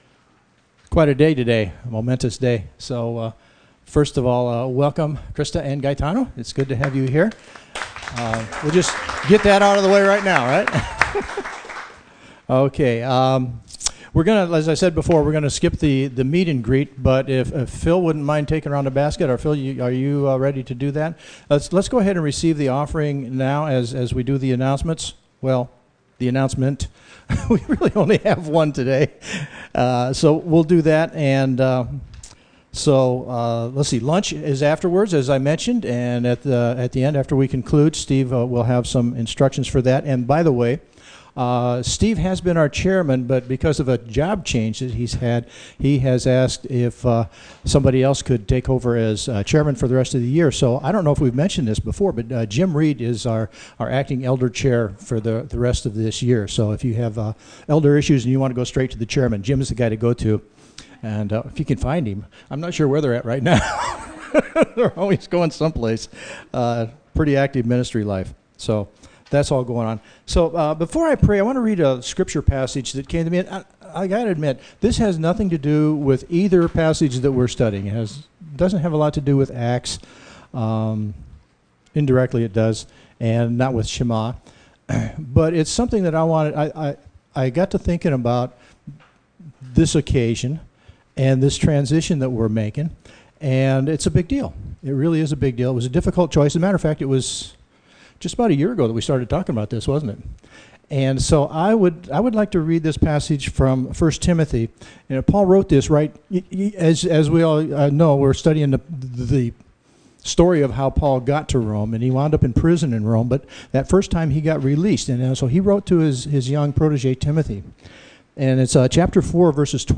Mark 12:28-31 Service Type: Gathering He’s here!